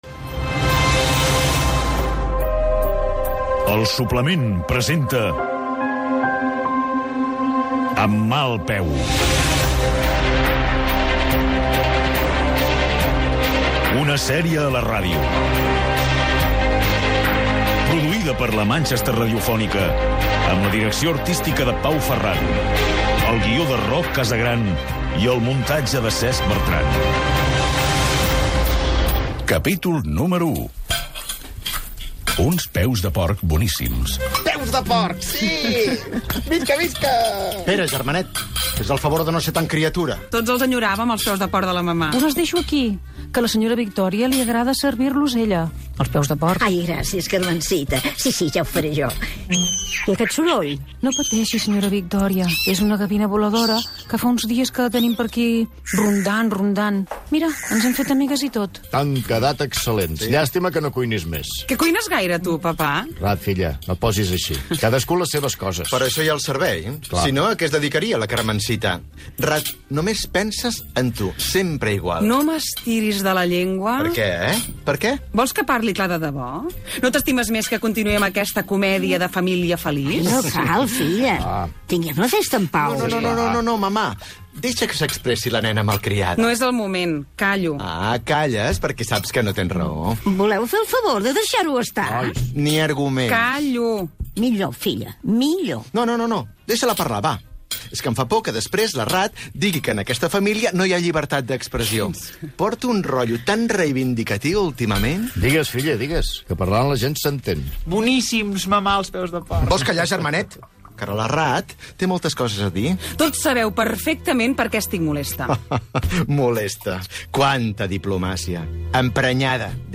"Amb mal peu". Capítol 1. Careta del dramàtic radiofònic. Escena a casa amb la família fent un dinar
Ficció